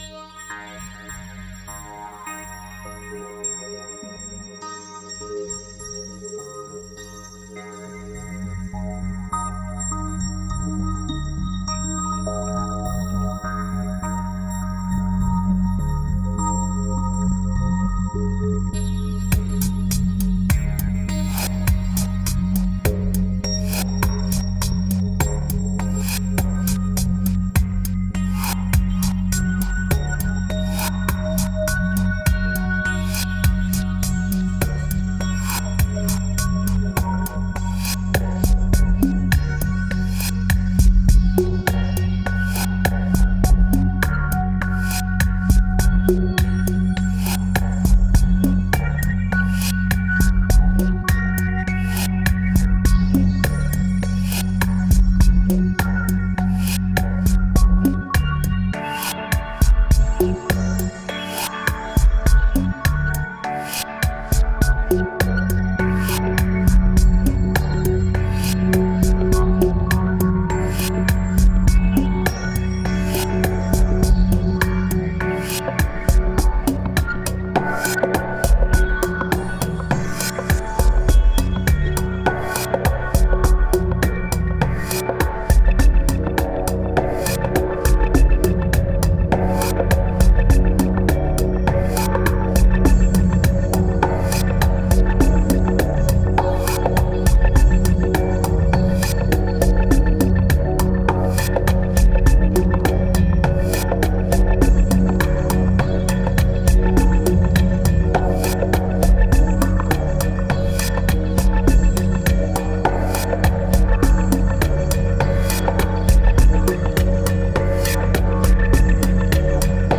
2174📈 - 7%🤔 - 102BPM🔊 - 2014-05-28📅 - -301🌟